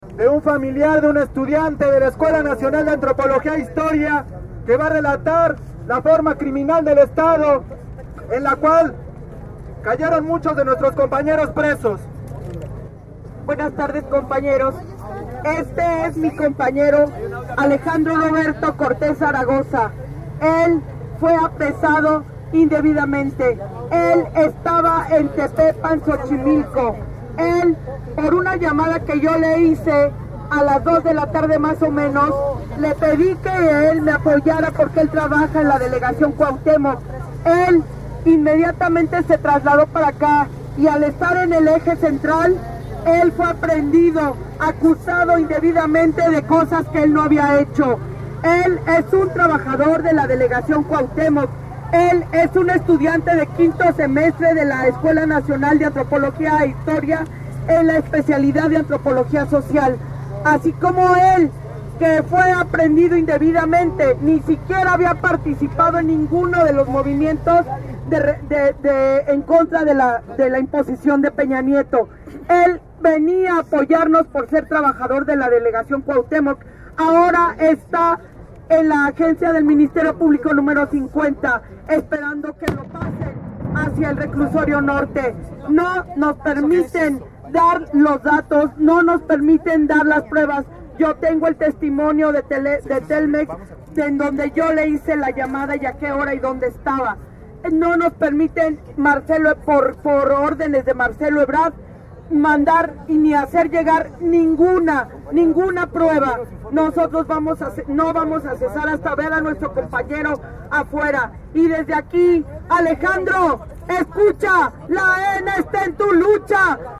Durante el mitin en el zócalo se vivía un ambiente de tranquilidad, silencio y atención a las palabras emitidas a través del megáfono.Los presentes escuchaban con atención sentados desde sus lugares en la plancha del Zócalo.